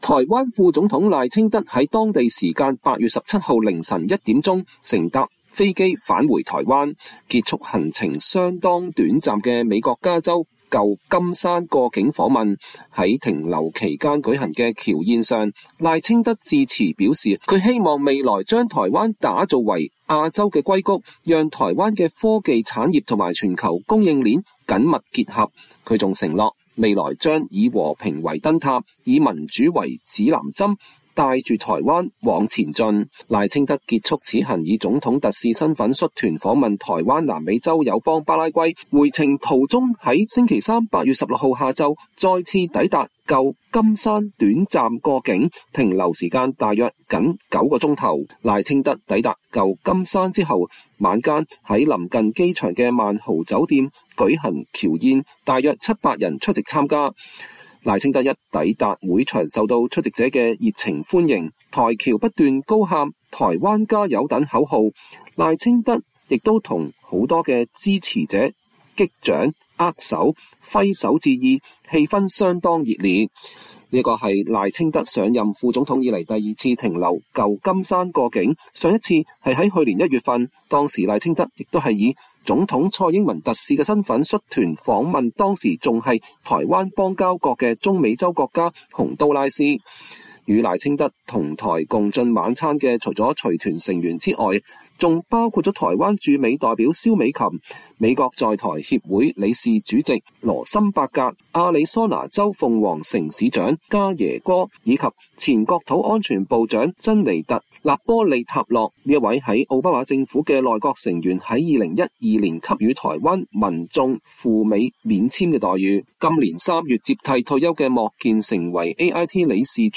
台湾副总统赖清德美西时间8月16日晚上在加州旧金山过境期间举行侨宴，上台发表演说。
“打造亞洲矽谷” 賴清德舊金山僑宴演說：以民主為指南針帶領台灣往前進
賴清德一抵達會場受到出席者的熱情歡迎，台僑不斷高喊“台灣加油”等口號，賴清德也與許多支持者擊掌、握手、揮手致意，氣氛相當熱烈。
賴清德在演說一開始先以台語致辭，向前來歡迎他的台僑表達感謝。